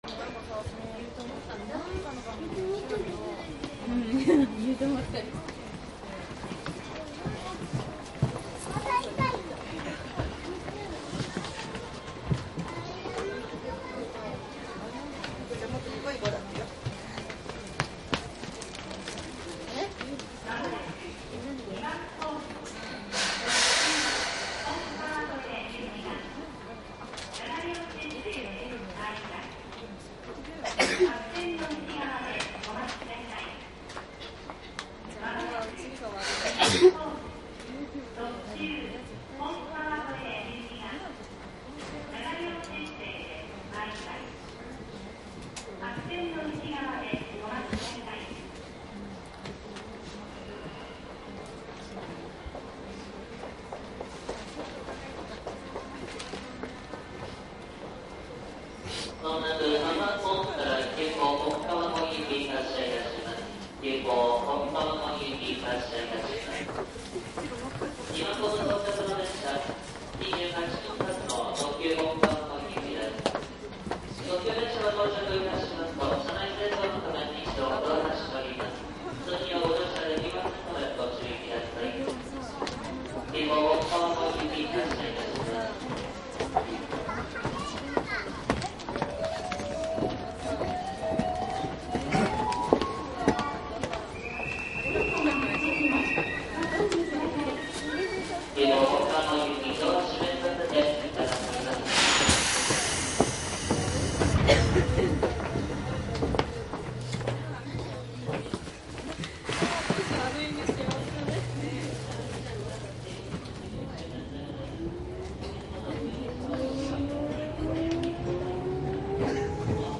西武新宿線2000系 急行本川越走行音♪
午後の下りで録音。鷺ノ宮から先は乗客の会話ほぼなし。
マスター音源はデジタル44.1kHz16ビット（マイクＥＣＭ959）で、これを編集ソフトでＣＤに焼いたものです。